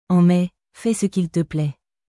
En mai, fais ce qu’il te plaîtアン メ フェ ス キル トゥ プレ